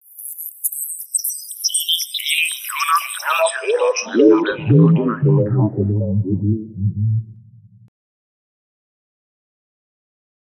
Computer Music